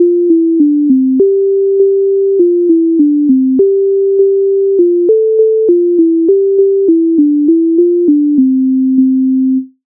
MIDI файл завантажено в тональності C-dur
Веселі гуси Українська народна пісня